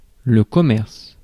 Ääntäminen
IPA: [kɔ.mɛʁs]